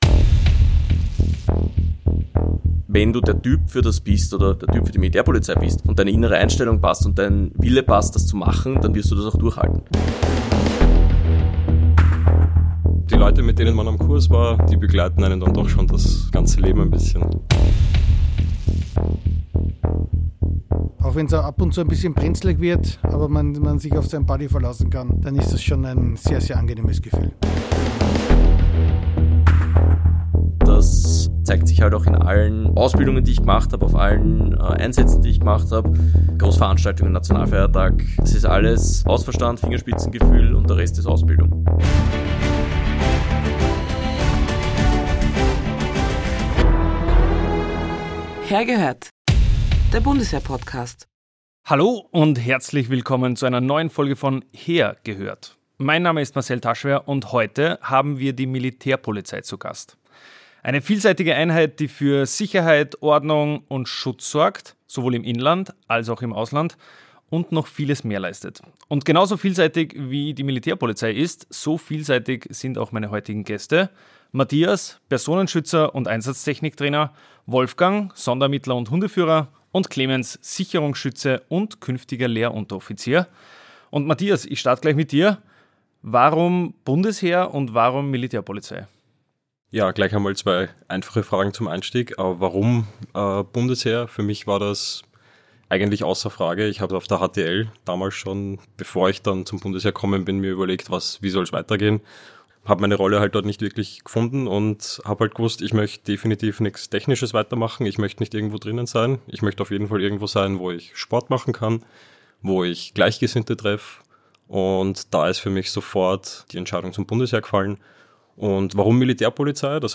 Die drei Soldaten erzählen von ihrem Weg zur Militärpolizei, wie sie die fordernde Ausbildung überstanden haben und berichten, was jemanden erwartet, der selber das Barett der MP tragen will.